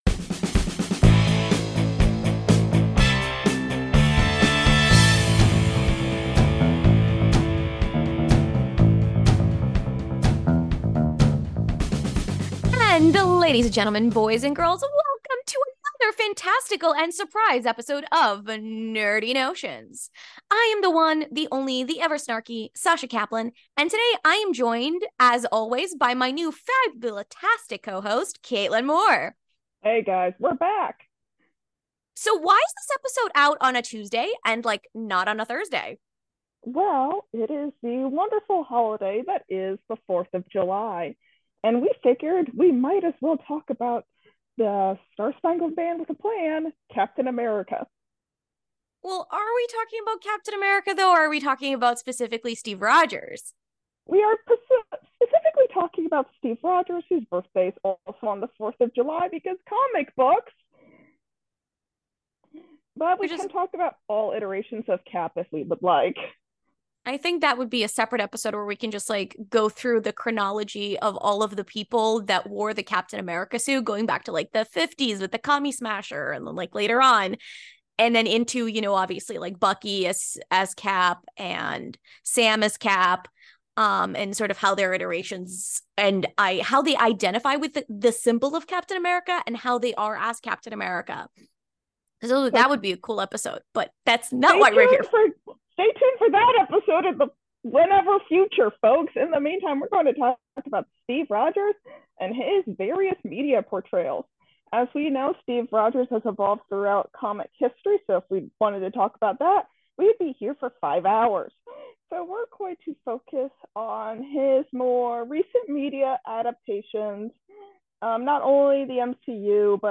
Opening Music